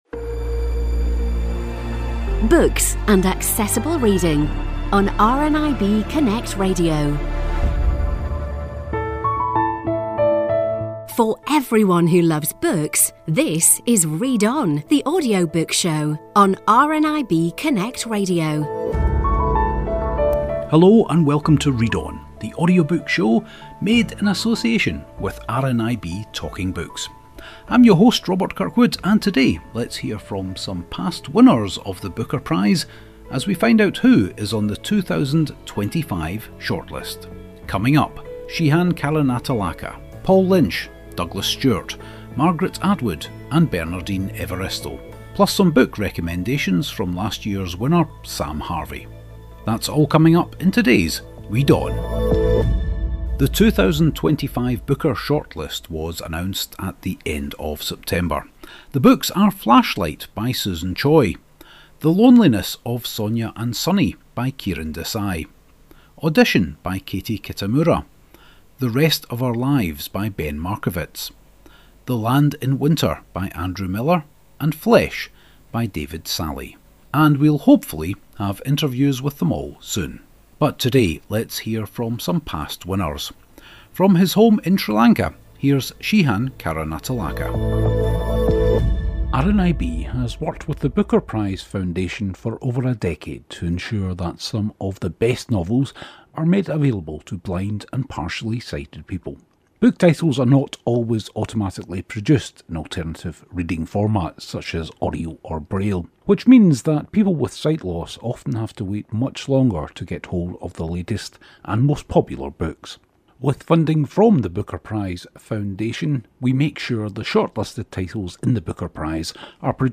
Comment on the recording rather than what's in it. A weekly show all about audiobooks recorded at the RNIB Talking Book studios. We talk to your favourite authors and narrators, along with reviews and news about new audiobooks.